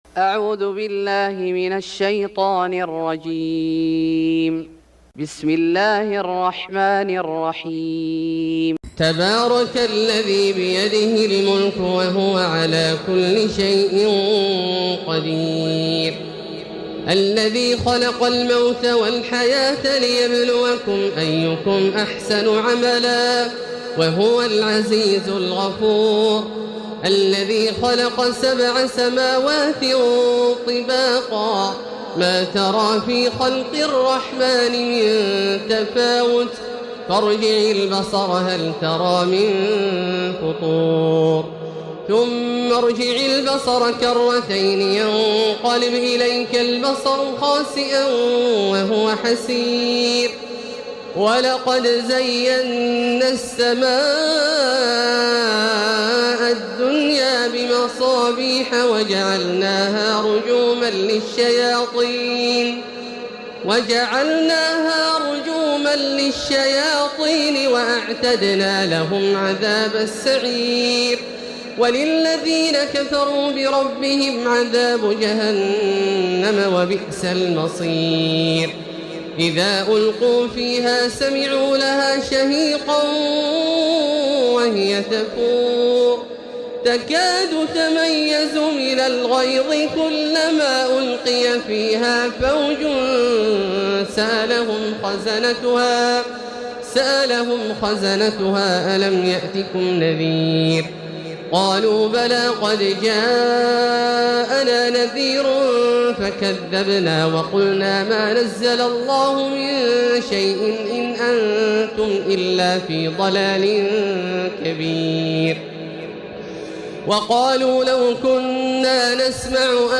سورة الملك Surat Al-Mulk > مصحف الشيخ عبدالله الجهني من الحرم المكي > المصحف - تلاوات الحرمين